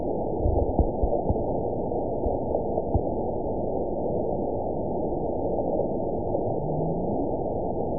event 920140 date 02/27/24 time 18:25:51 GMT (1 year, 9 months ago) score 7.59 location TSS-AB04 detected by nrw target species NRW annotations +NRW Spectrogram: Frequency (kHz) vs. Time (s) audio not available .wav